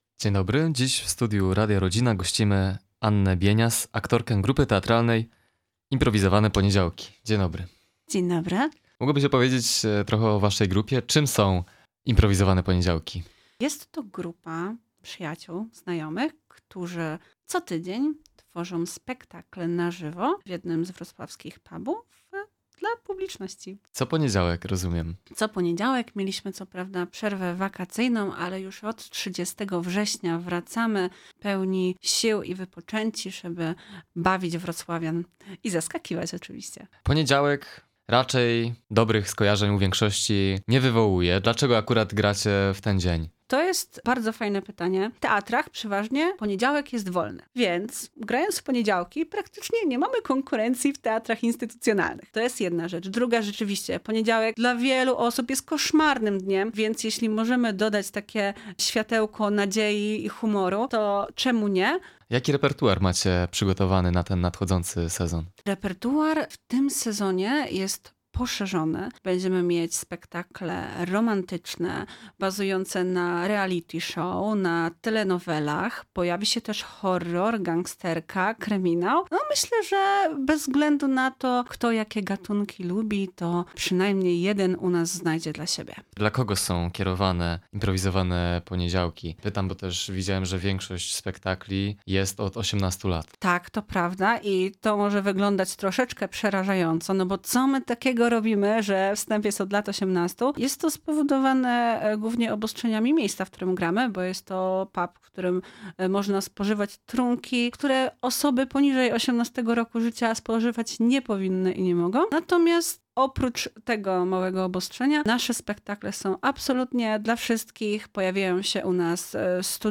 CAŁA ROZMOWA: